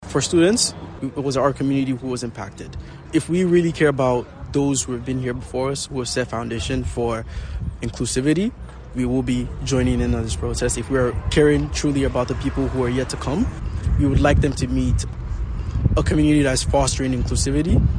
Outside Peterborough City Hall was a bit busier than normal for a regular Monday night meeting.